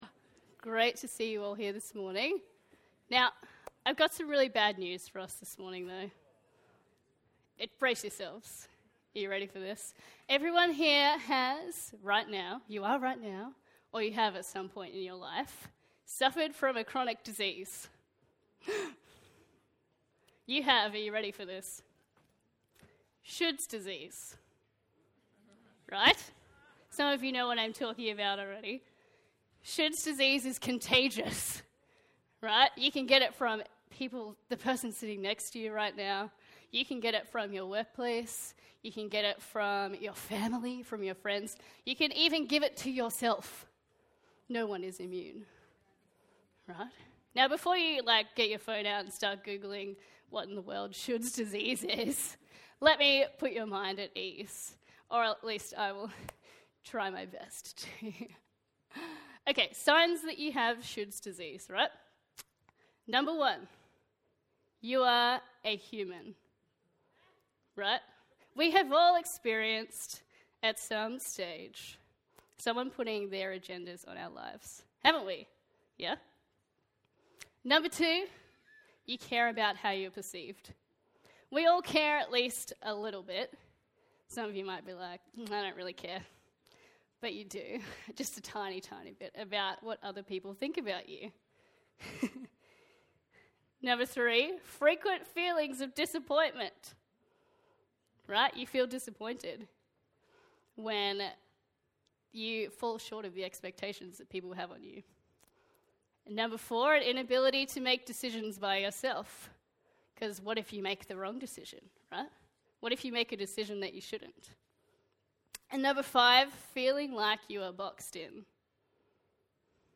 A message from the series "Who you say I am."